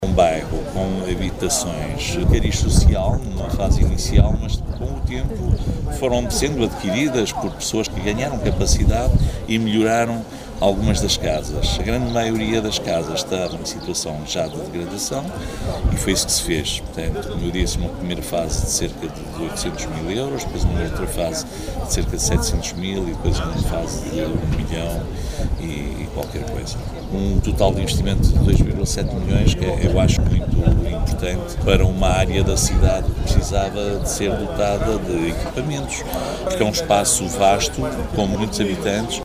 Já foi inaugurada a requalificação do Bairro Social São Francisco, em Macedo de Cavaleiros, para a qual foram investidos cerca de 2,7 milhões de euros, em intervenções que faziam falta para quem habita naquela zona da cidade, justifica o presidente do Município, Benjamim Rodrigues: